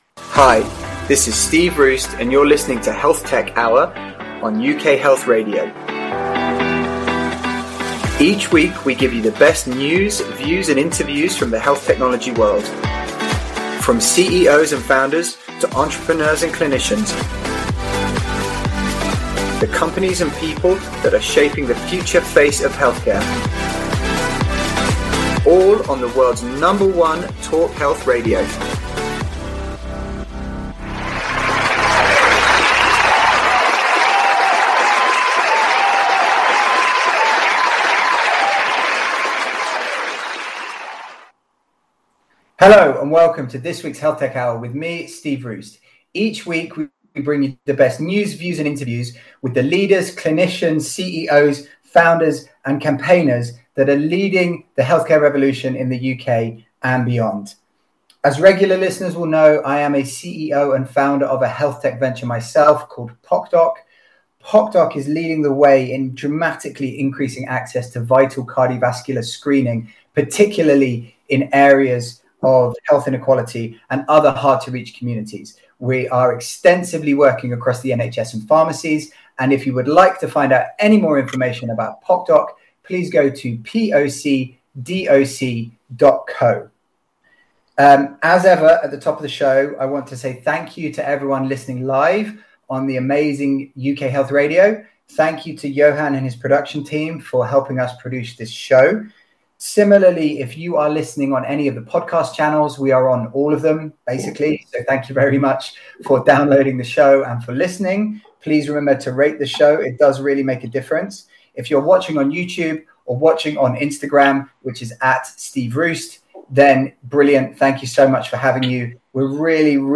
There is also a robust and honest discussion about HRT and non-HRT treatment methods as well as the pros and cons of social media.